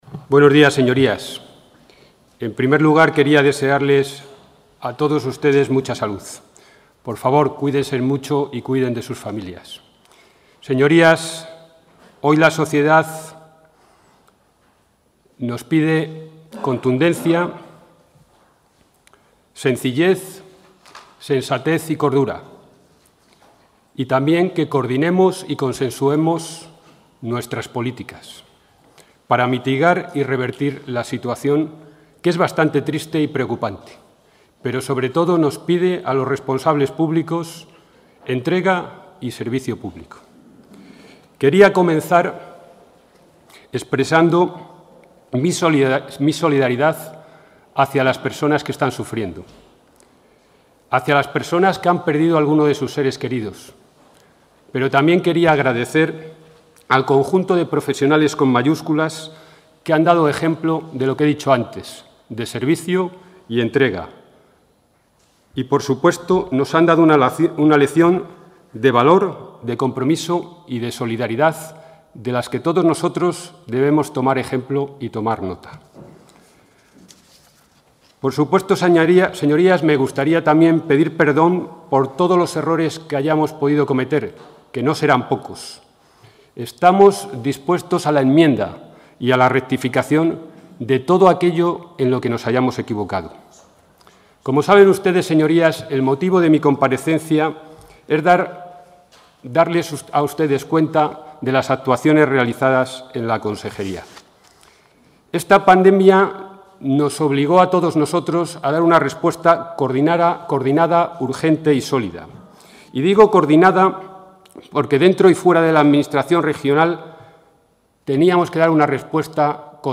Comparecencia.
El titular de Empleo e Industria de la Junta, Germán Barrios, ha comparecido esta mañana en la sede de las Cortes de Castilla y León para detallar las iniciativas llevadas a cabo por su departamento con motivo de la crisis originada por el COVID-19. Allí ha explicado que toda la acción ejecutiva de la consejería que dirige se ha orientado en las últimas semanas hacia un objetivo común: la protección de los puestos de trabajo.